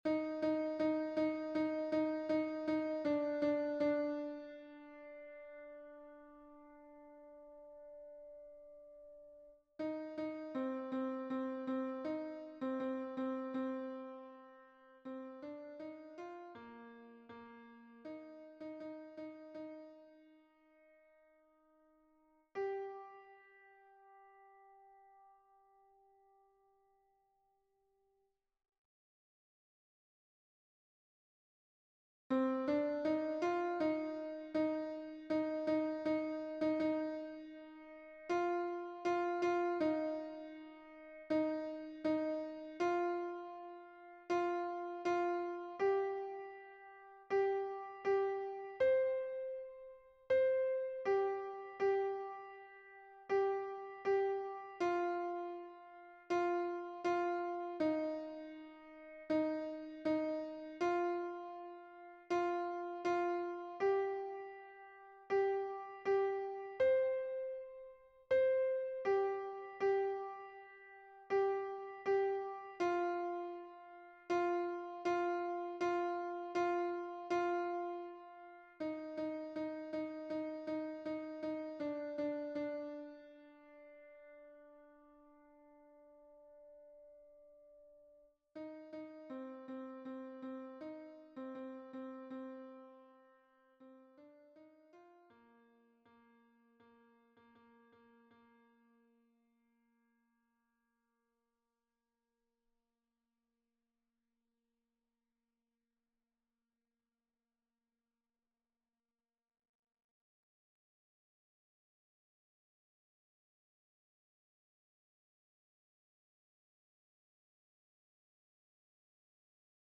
MP3 version piano
Alto 1